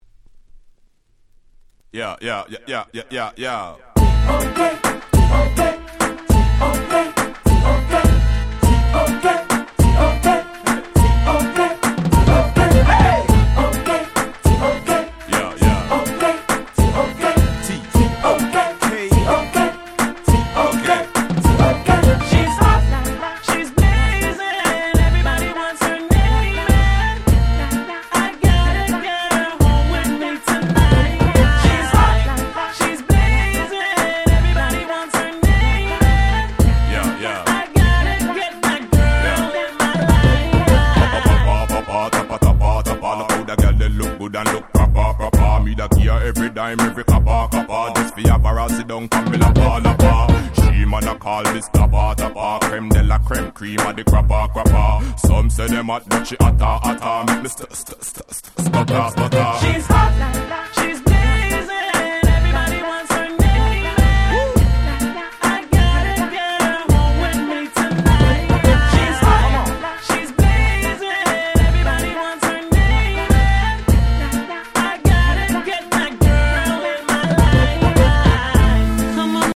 03' Smash Hit Dancehall Reggae !!